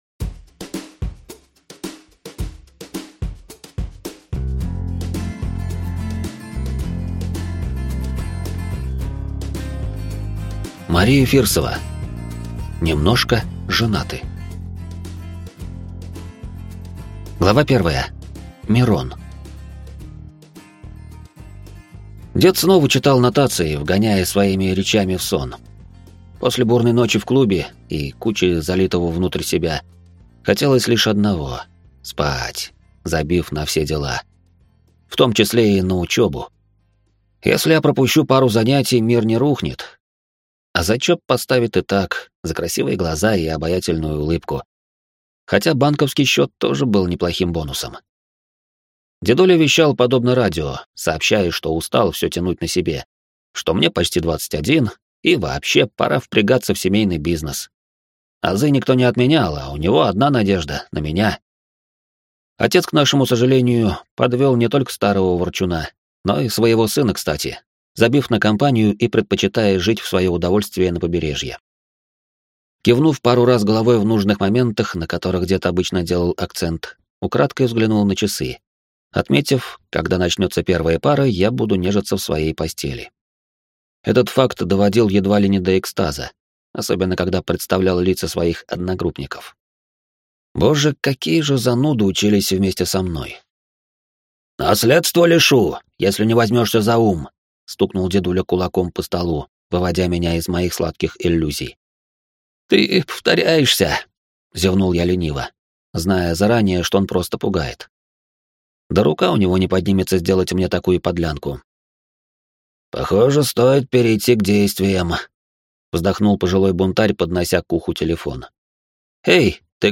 Аудиокнига Немножко женаты | Библиотека аудиокниг